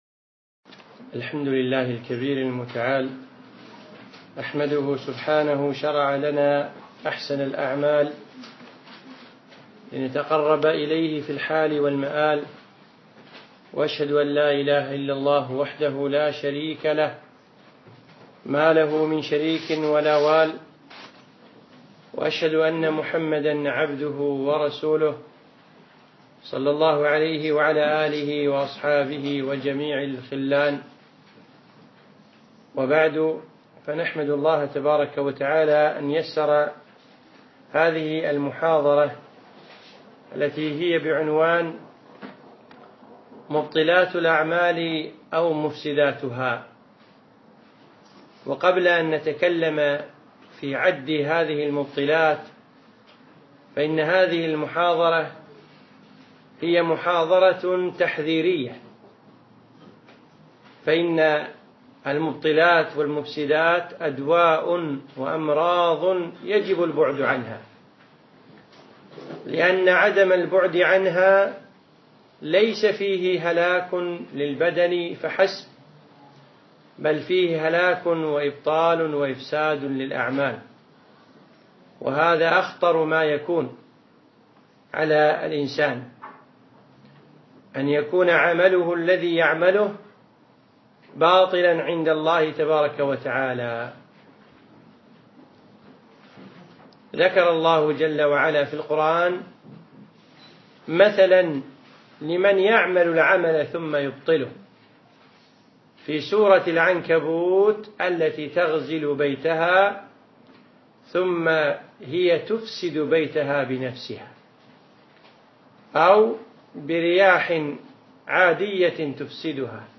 أقيمت المحاضرة يوم الثلاثاء 14 ربيع ثاني 1436 الموافق 3 2 2015 في مركز القصر نساء مسائي